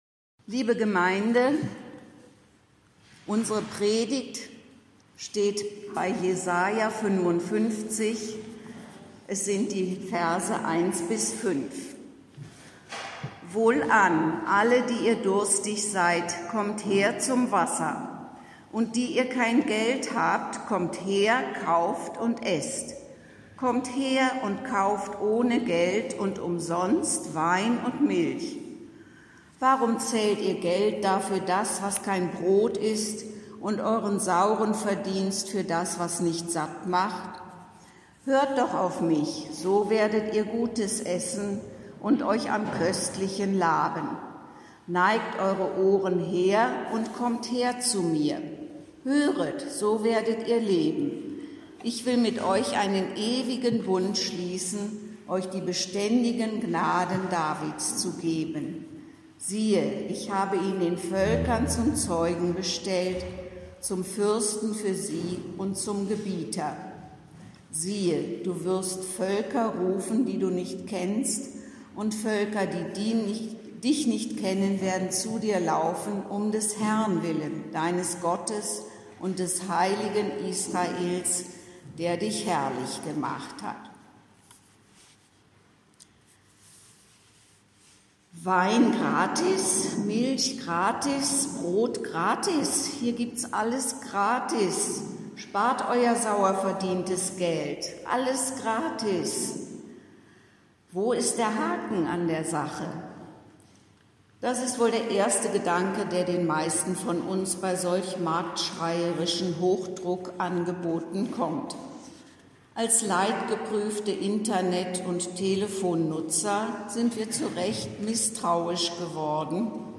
Predigttext: Jes 55,1–5